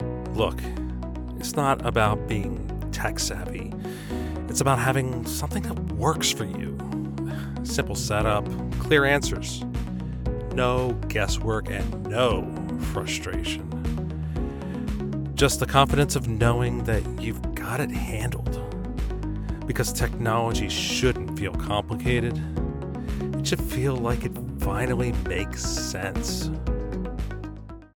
Tech Commercial Spot